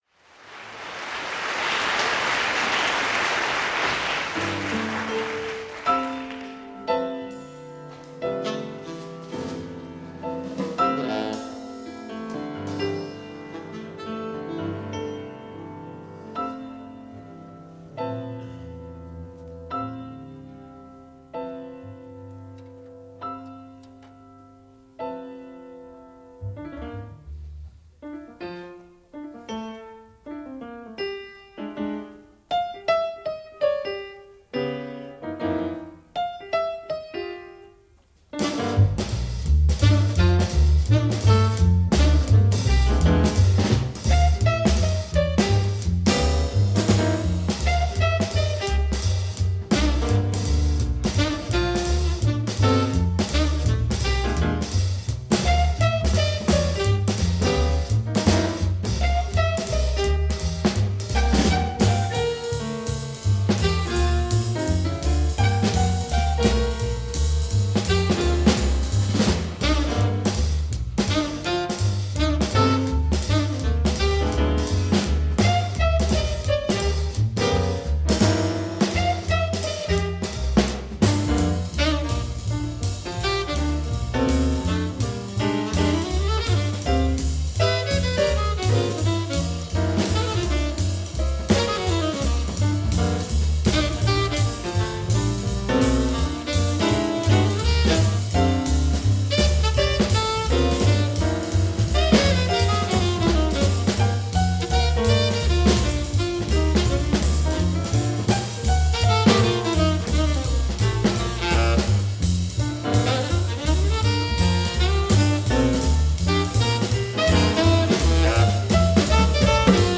tenor sax
bass
drums.
Bop